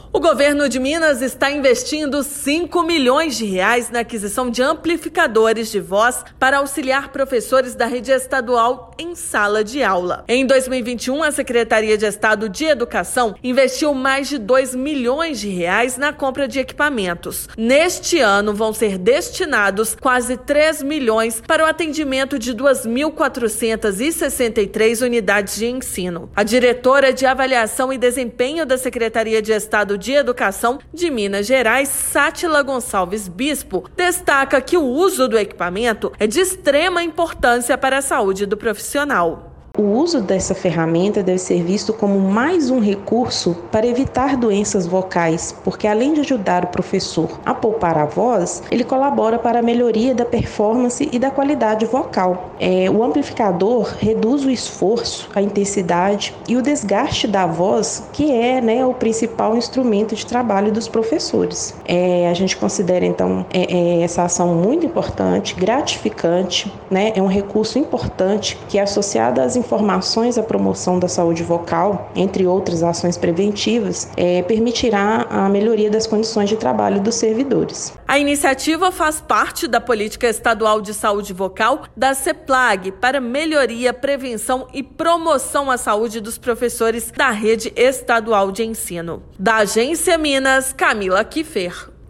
Iniciativa faz parte da Política Estadual de Saúde Vocal da Seplag para melhoria, prevenção e promoção à saúde dos professores da rede estadual de ensino. Ouça a matéria de rádio.